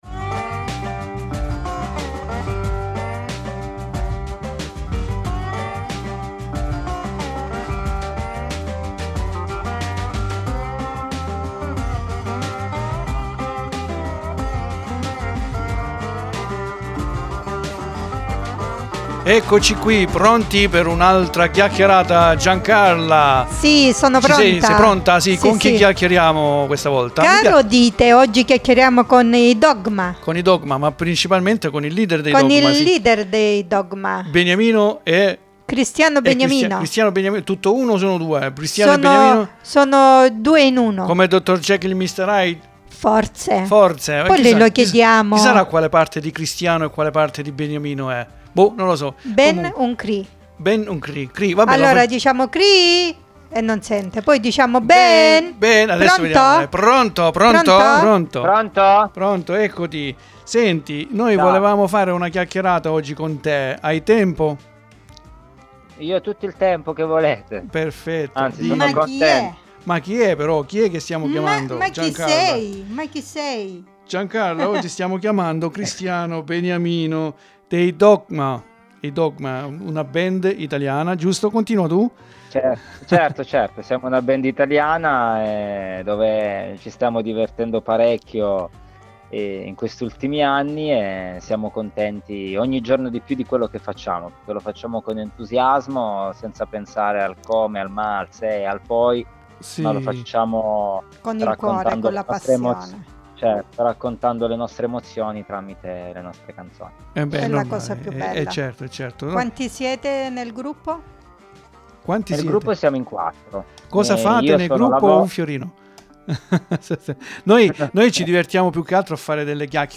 QUINDI NON RIMANE ALTRO CHE AUGURARVI UN BUON ASCOLTO E GUSTATE OGNI SINGOLA PAROLA E NOTA DEL SUO BRANO E ANDATE A CERCARE GLI ALTRI NEGLI STORE , NE VALE LA PENA !